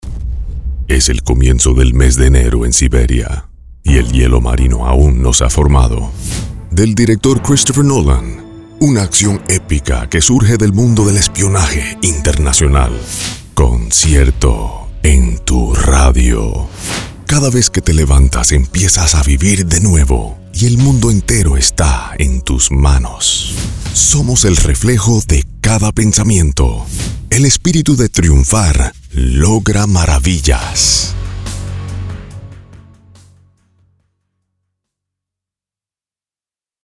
Male
Adult (30-50)
Singing